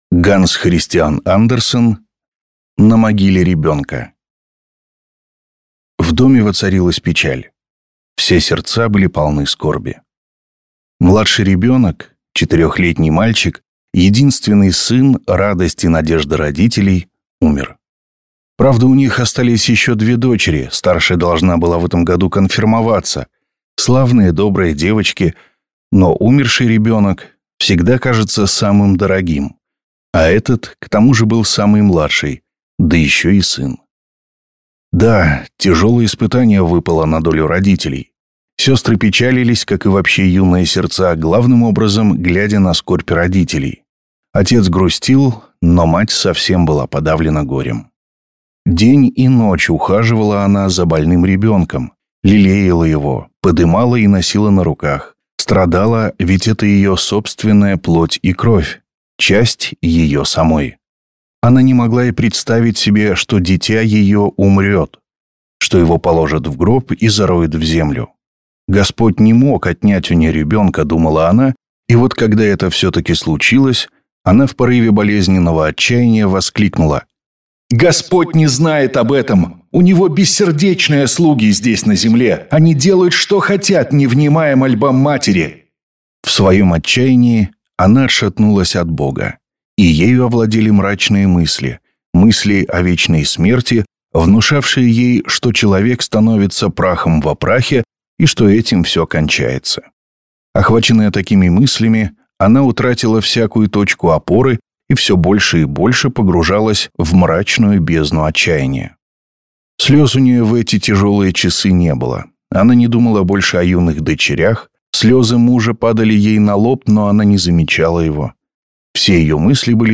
Аудиокнига На могиле ребёнка | Библиотека аудиокниг